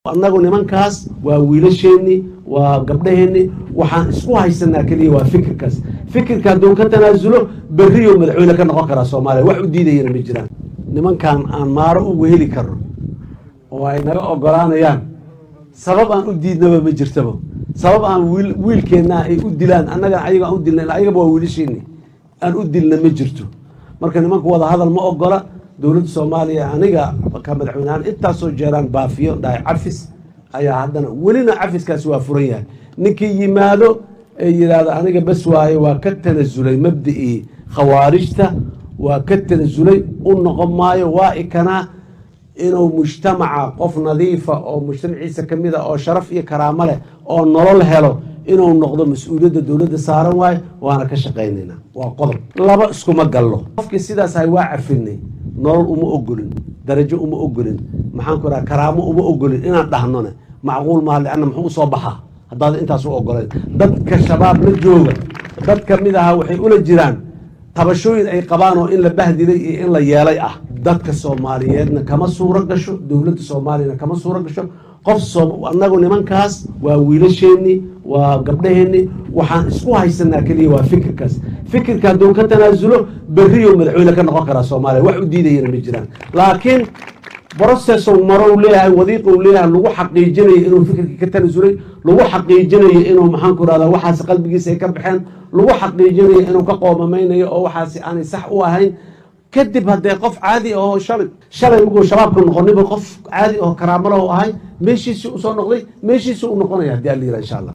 Madaxweynaha dalka Soomaliya, Xasan Sheekh Maxamuud oo xalay ka qayb galay kulan dadweyne oo su’aalo lagu weydiinayay oo ka dhacay magaalada Dhuusamareeb oo uu hadda ku sugan yahay, ayaa ka hadlay arrimo dhowr ah oo la xiriira kooxda Al-Shabaab.